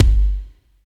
28 KICK 2.wav